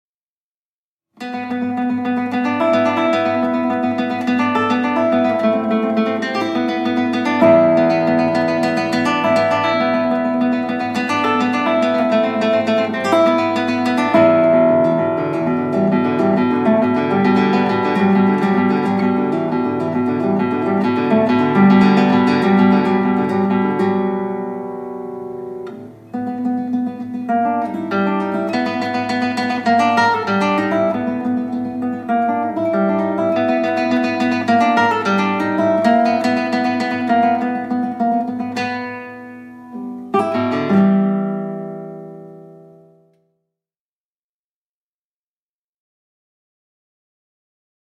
Calmo   1:17